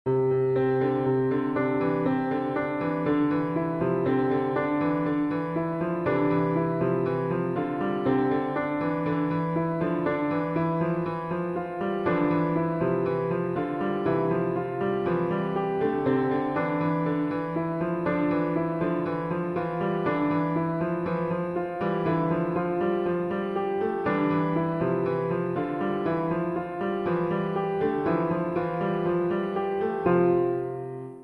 piano_mix.ogg